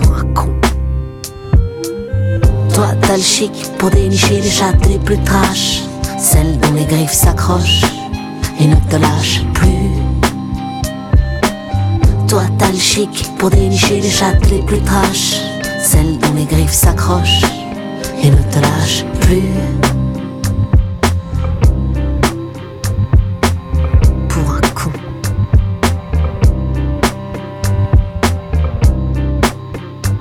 "enPreferredTerm" => "Chanson francophone"